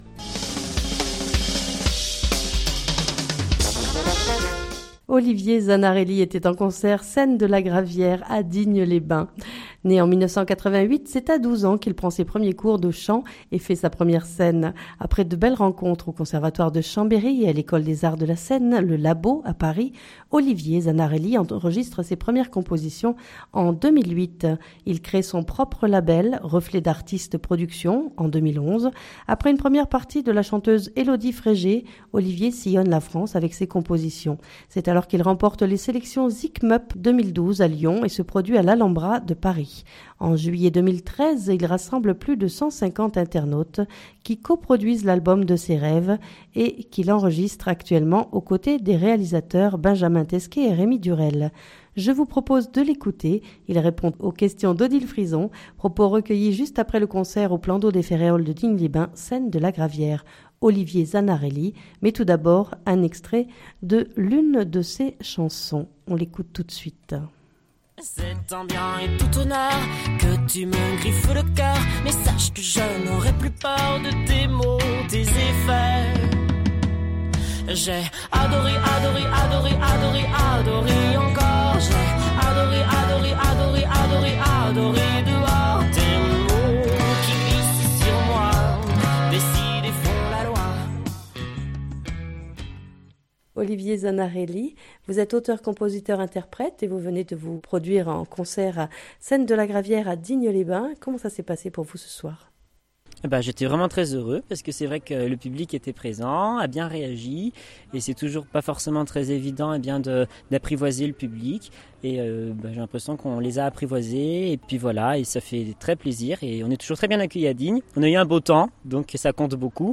Propos recueillis juste après le concert au plan d’eau des Férréols de Digne-les-Bains, scène de la gravière.